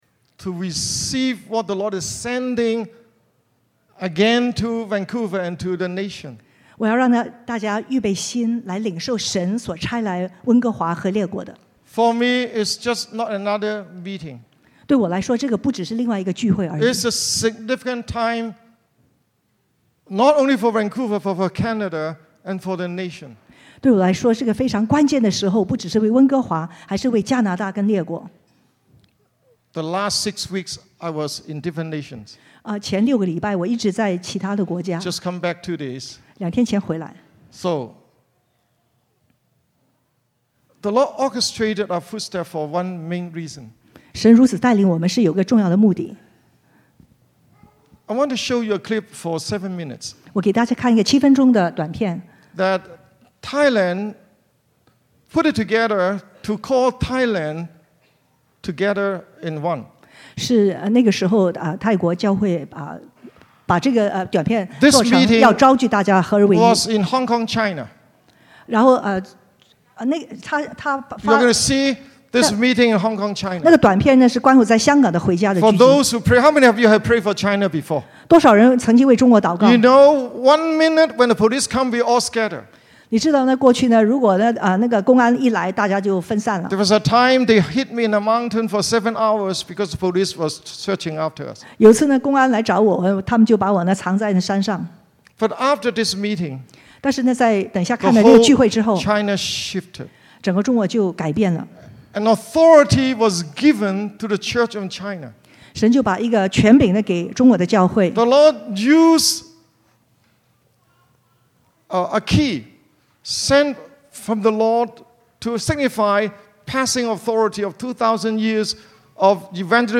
城市复兴特会（1）